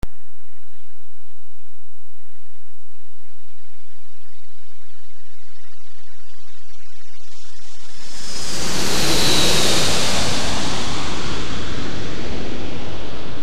Mp3 Surround Effects by Mp3 Arena
Plane 419Kb  0:13 256 Sur What if a plane flew through your room?
Plane.mp3